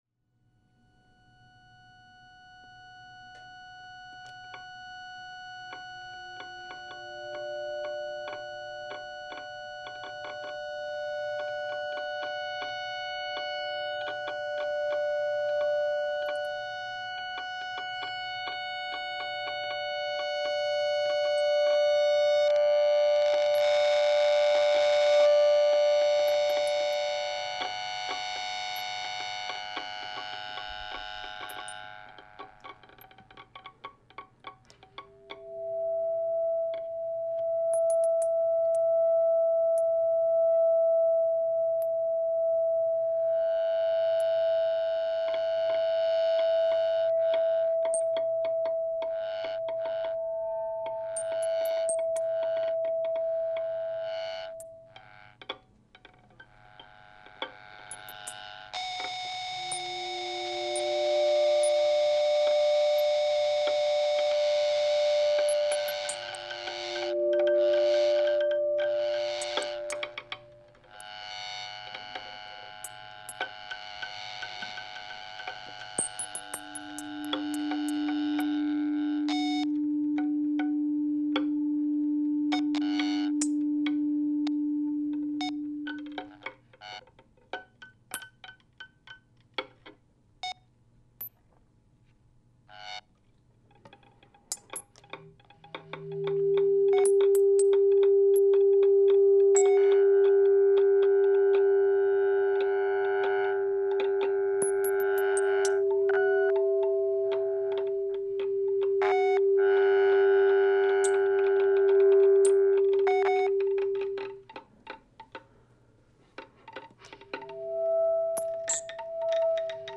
violin
guitar, electronics
bass clarinet
Swedish quartet
free improvised music